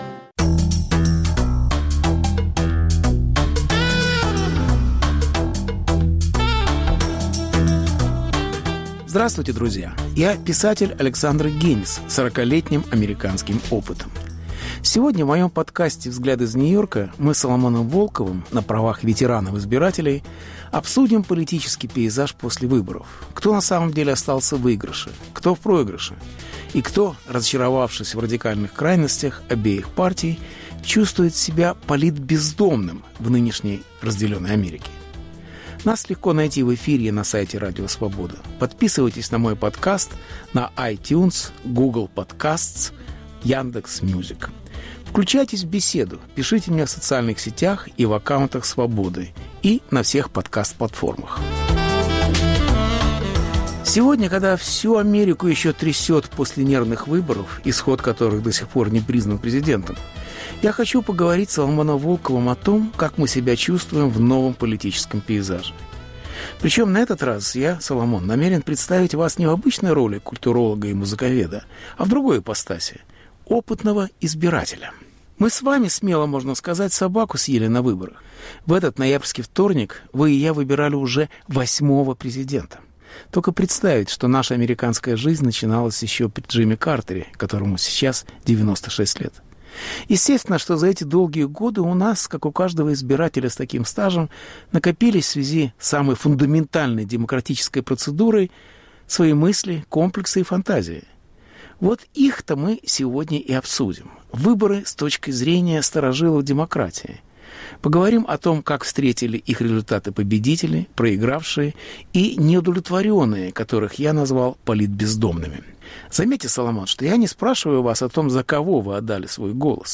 Беседа с Соломоном Волковым об американском политическом пейзаже после выборов.